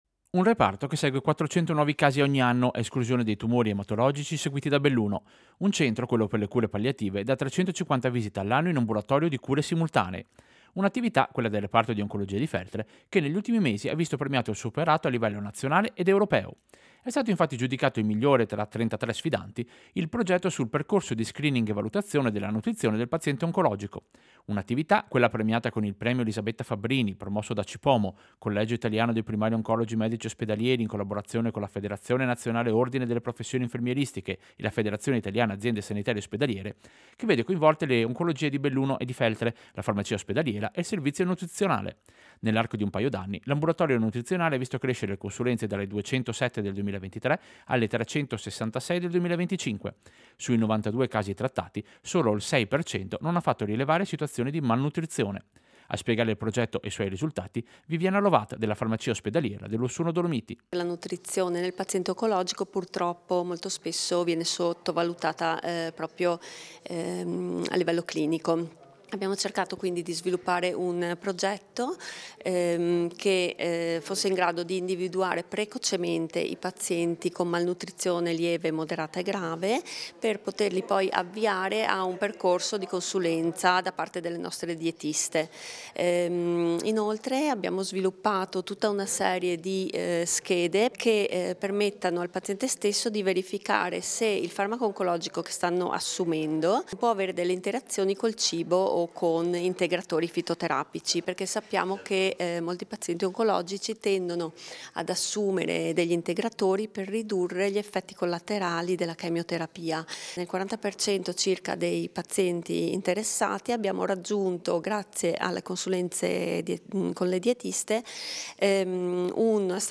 Servizio-Oncologia-Feltre-e-riconoscimenti.mp3